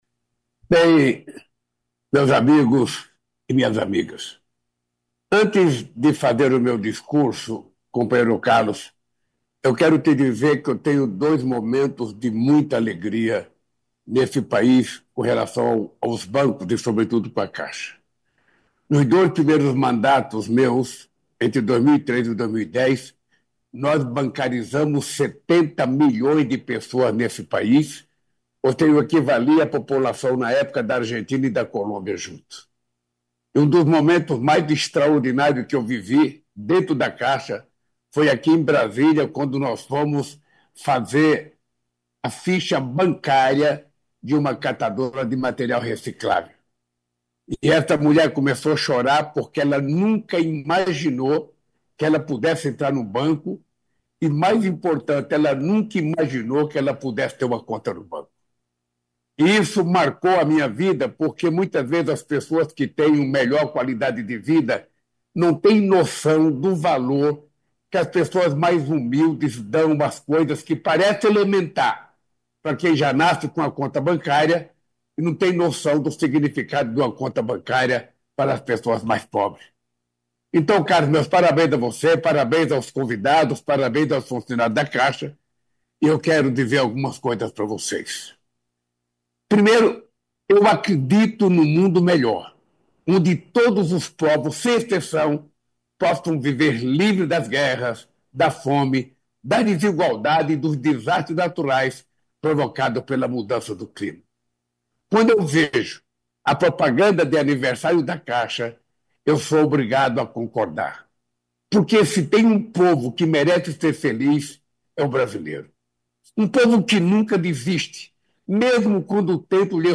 Presidente Lula faz pronunciamento em rede nacional de rádio e TV
Íntegra do pronunciamento do presidente da República Luiz Inácio Lula da Silva, nesta segunda-feira (23)